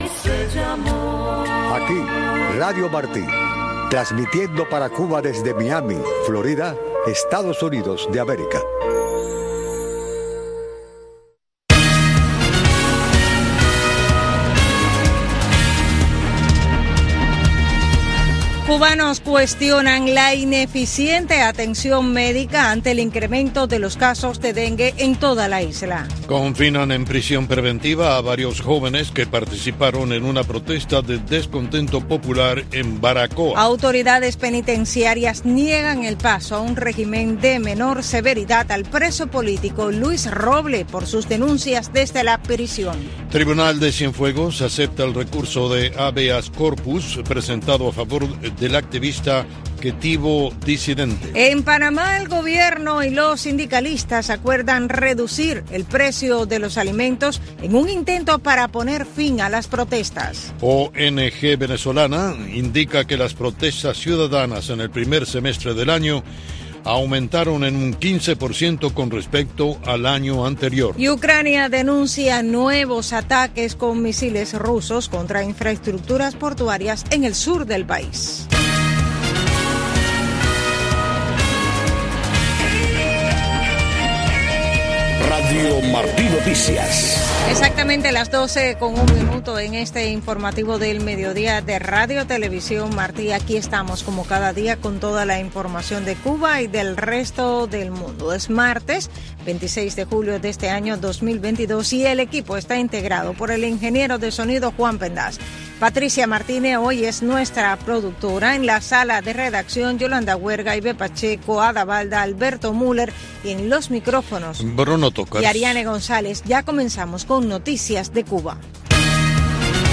Noticiero de Radio Martí 12:00 PM